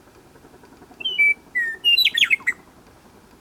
Western Meadowlark.